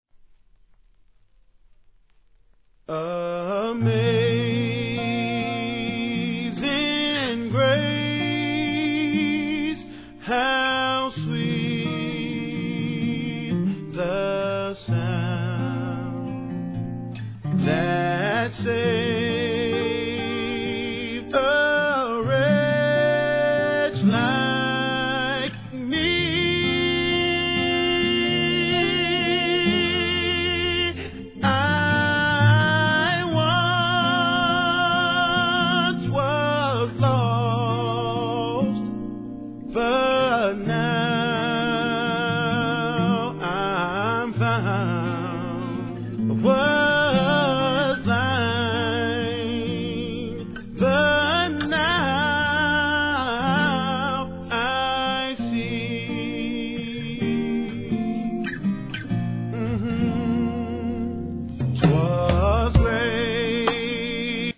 1. HIP HOP/R&B
スロウでムーディー、甘〜いセレクトで今回もバカ売れ確実！！